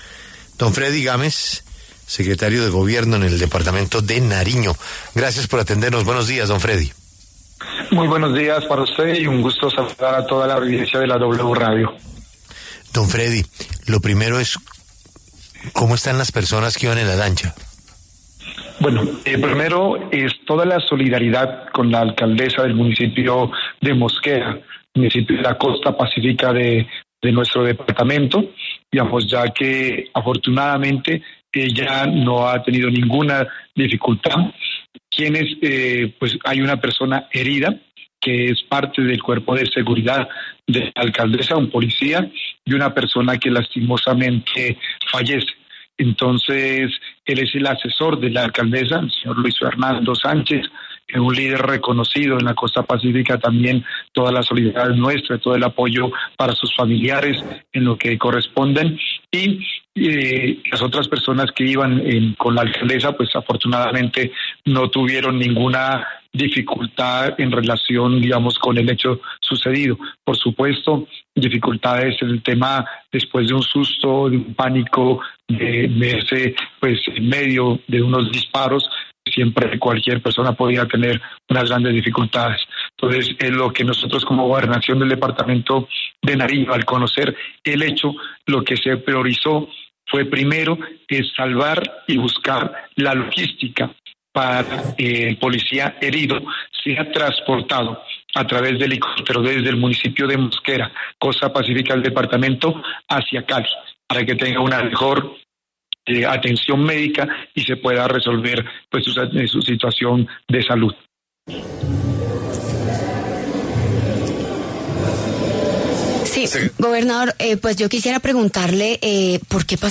El gobernador encargado de Nariño, Fredy Andrés Gámez, conversó con La W sobre el reciente ataque contra el ataque a una embarcación de la alcaldesa de Mosquera.